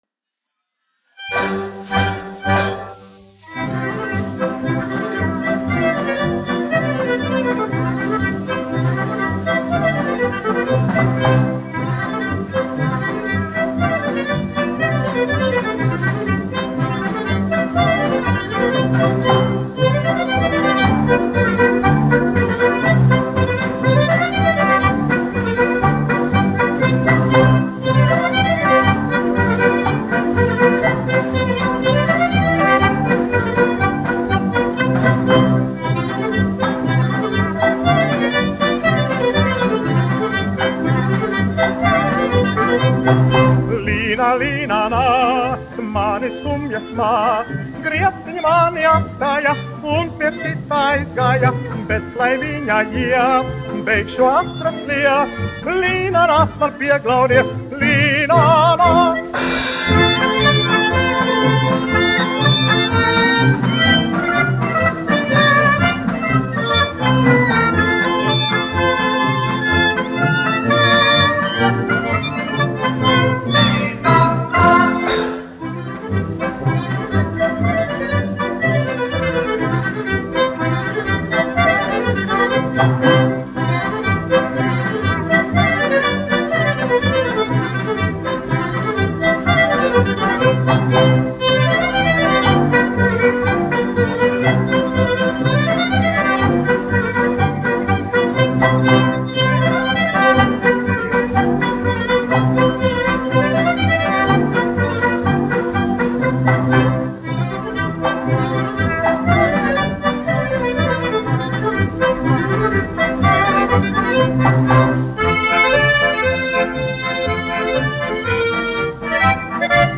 1 skpl. : analogs, 78 apgr/min, mono ; 25 cm
Populārā mūzika
Polkas
Skaņuplate